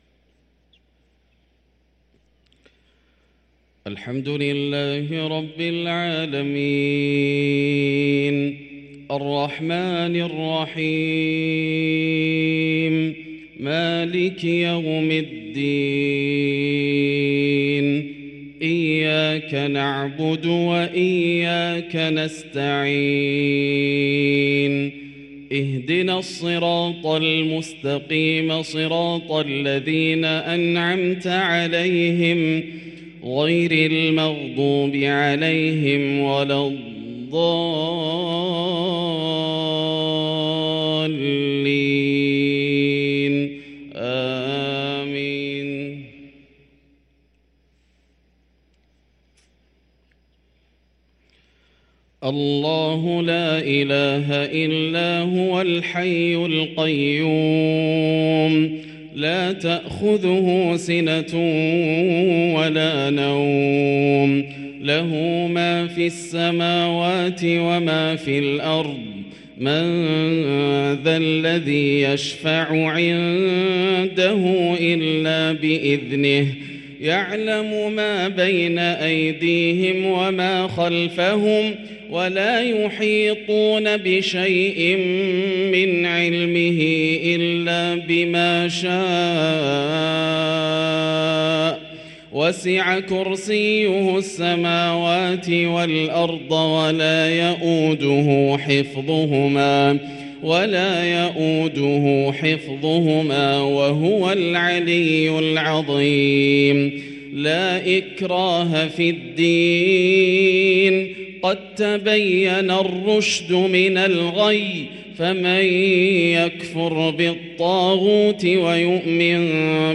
صلاة العشاء للقارئ ياسر الدوسري 23 رجب 1444 هـ
تِلَاوَات الْحَرَمَيْن .